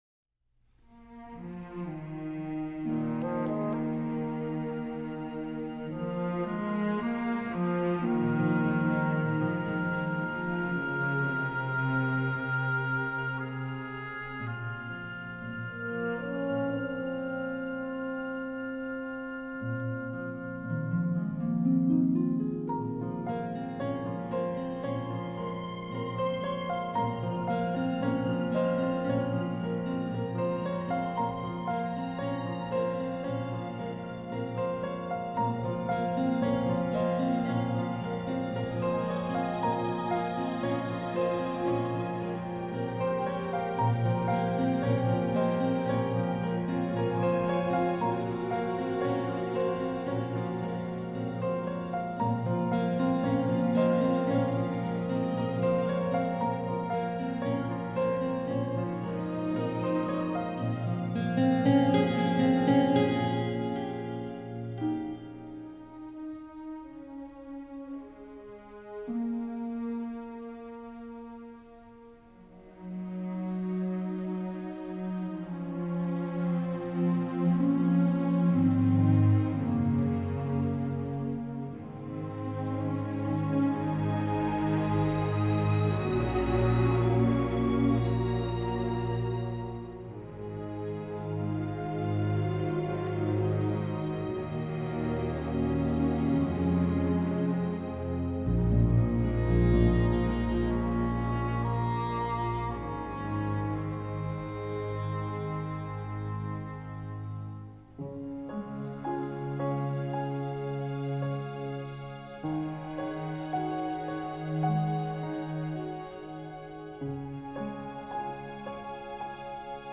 广告片背景音乐